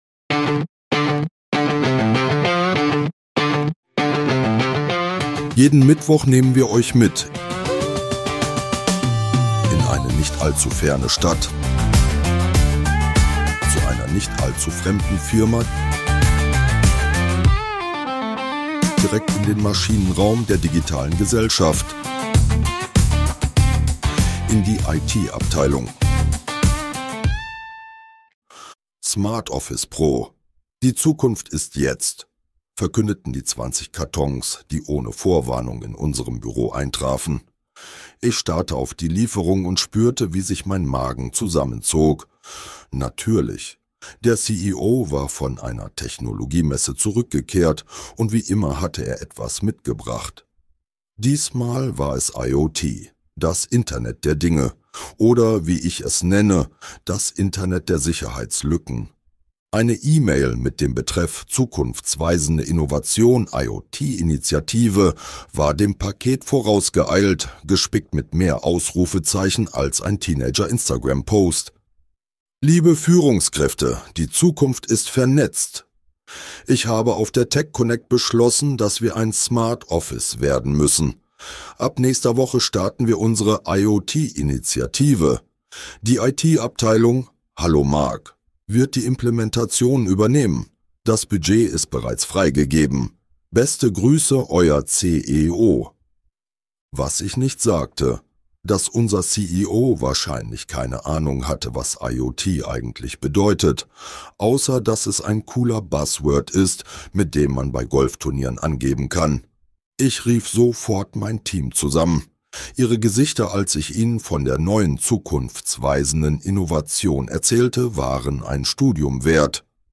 Zwischen Bugs, Buzzwords und blindem Aktionismus stellt sich die eigentliche Frage: Wann wird endlich mal eine Entscheidung smart? Mit dabei: IoT-Wahnsinn im Büroalltag Kaffeemaschinen mit Eigenleben Toilettensitze, die zu viel wissen Ein CEO mit großem Technikhunger – und kleinem Sicherheitsverständnis Dieser Podcast ist Comedy.
(AI generiert) Mehr